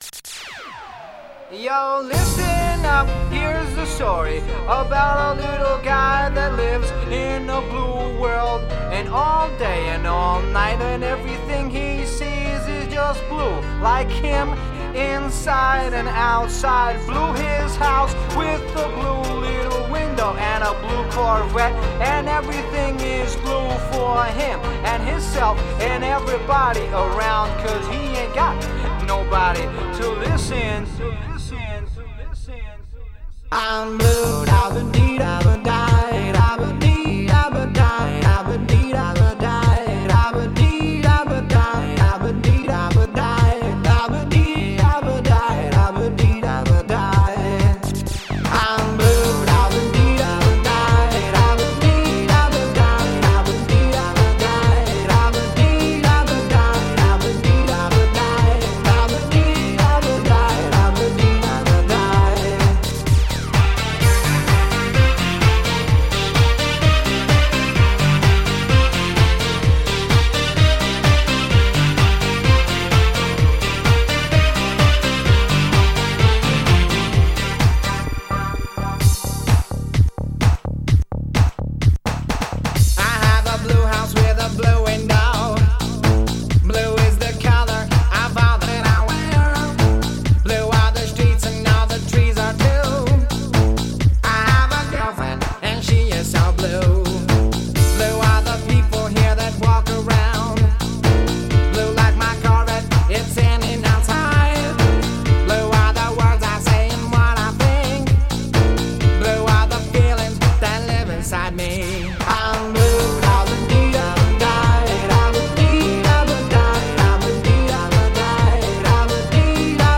EDM 90er